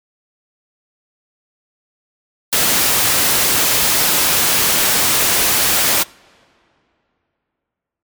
なのでFXをON→REVERBをON→MIXを5％ぐらいでいい感じかなと思います。
さっきより自然な感じになりましたよね！！